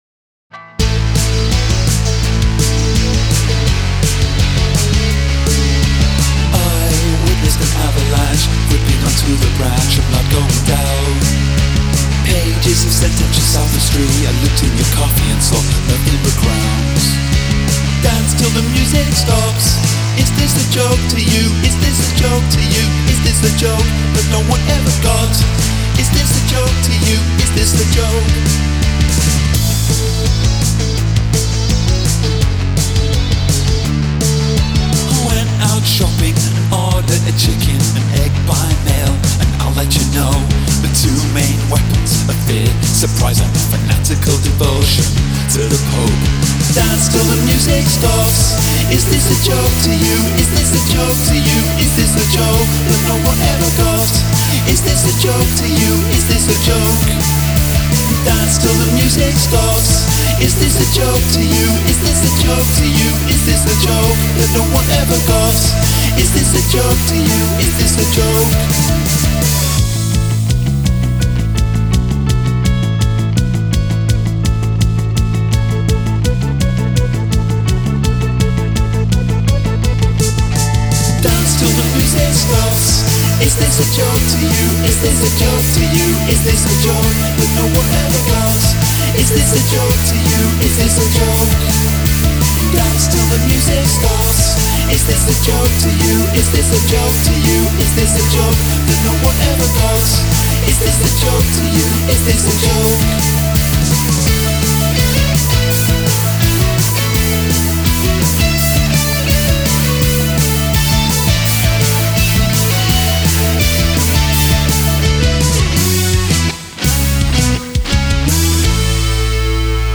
Very boppy. Some roughness, but a catchy chorus.